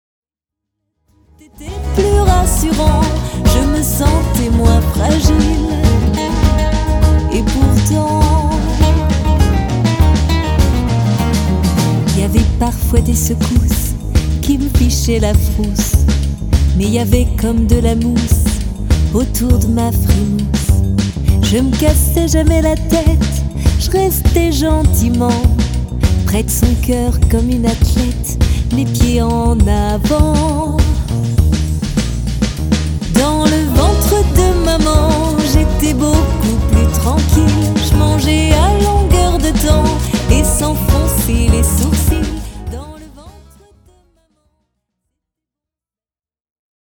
Une voix qui enchante...